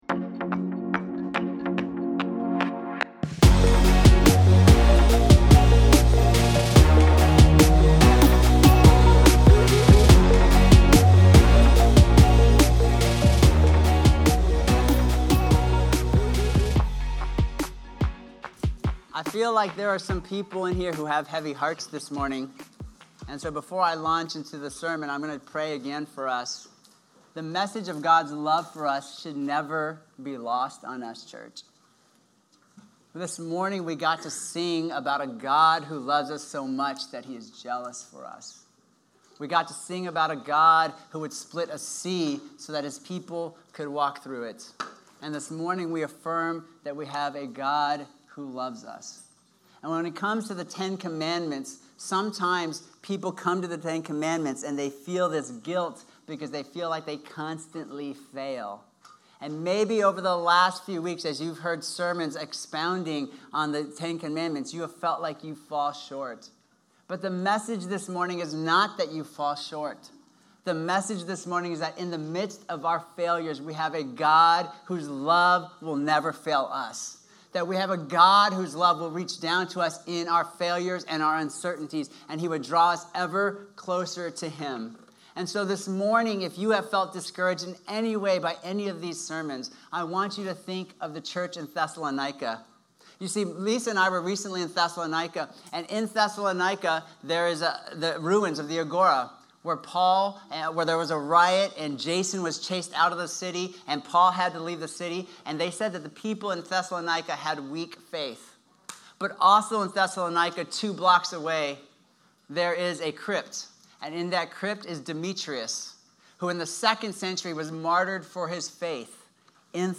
Sermons | RISEN CHURCH SANTA MONICA, INC.